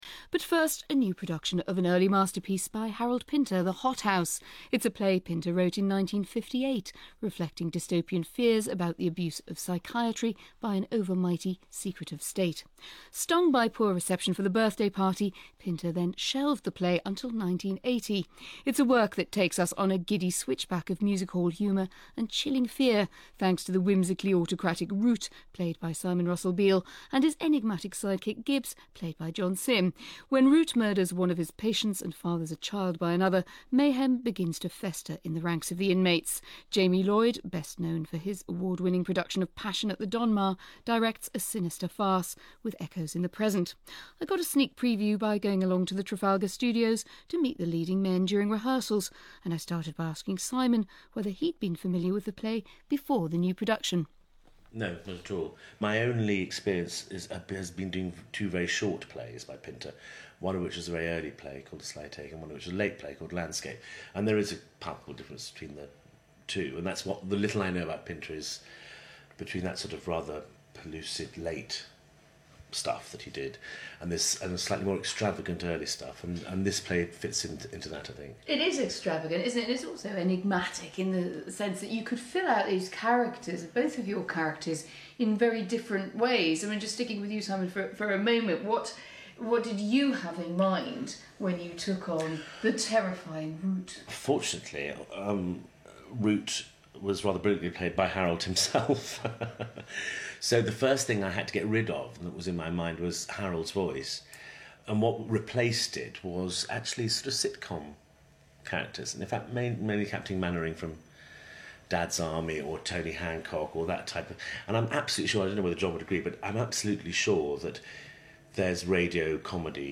BBC Radio 3 Anne McElvoy Broadcast 9 May 2013 In Night Waves this evening, presenter Anne McElvoy applies herself to a masterpiece of British theatre, talking to Simon Russell Beale and John Simm about Pinter's early tragicomedy, The Hothouse. (00:16:19) Listen to interview and an impromptu example scene by John Simm and Simon Russell Beale…
bbc-radio-3_john-simm-and-simon-russell-beale-with-anne-mcelvoy_may-2013.mp3